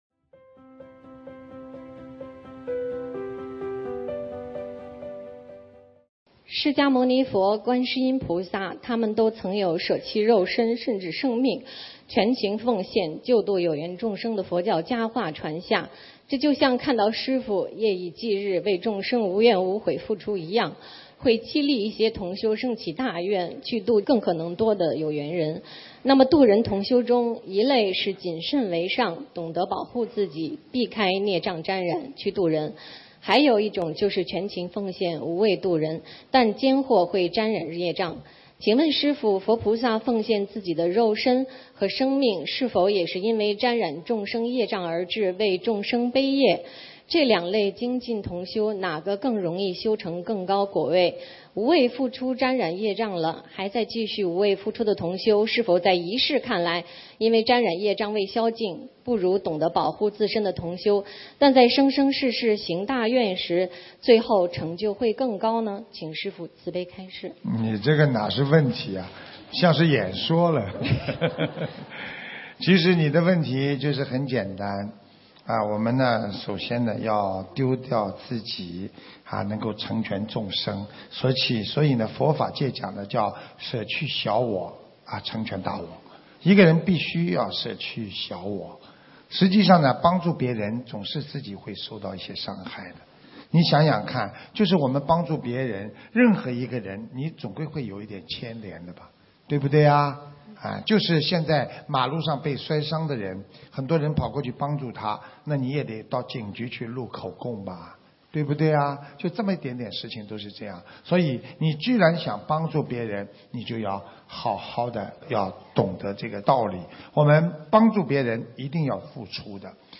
心灵净土»心灵净土 弘法视频 法会节选 视频：128.自度度人要舍弃小我！
音频：自度度人要舍弃小我！成全大我！2016年8月12日！马来西亚槟城！世界佛友见面会共修组提问